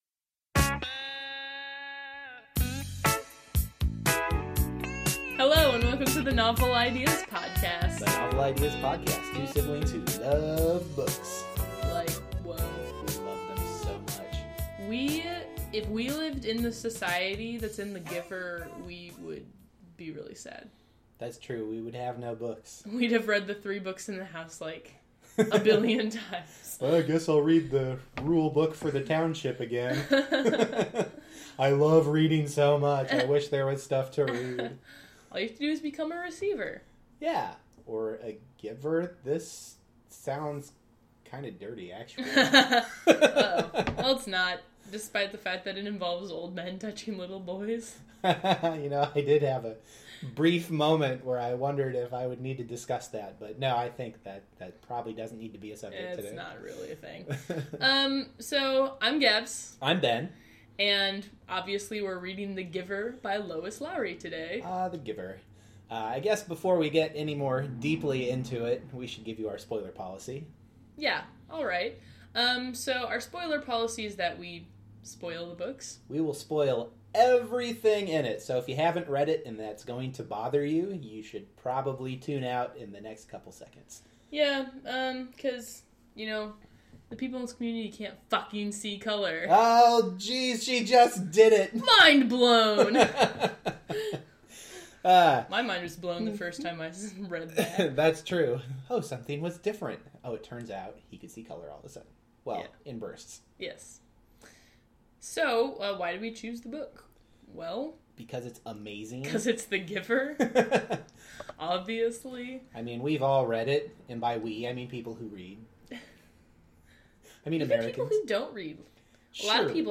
This week’s podcast is a colorful discussion of the world of sameness, brought to you by insightful blue-eyed siblings who have a strangely hard time keeping memories of a book they just finished reading. The professional quality of the broadcast is quite evident this week with special guest appearances from sirens, motorcycles, and the neighbor’s dog.